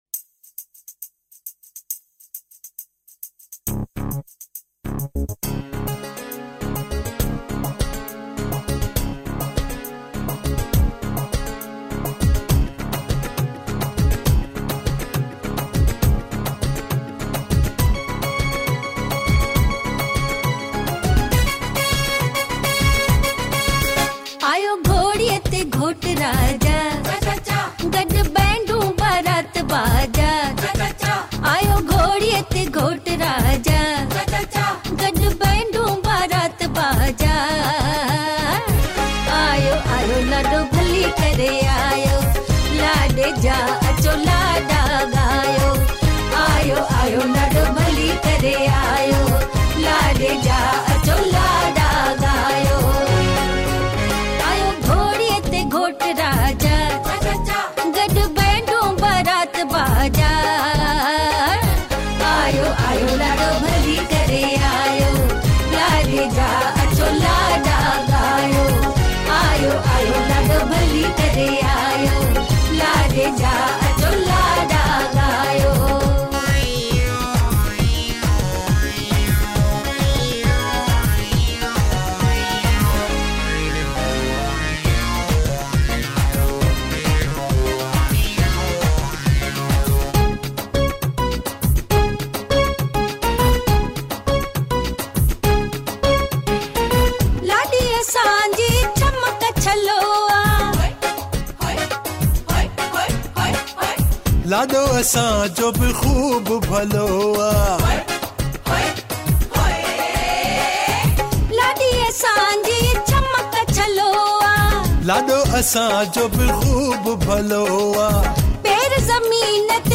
Sindhi POP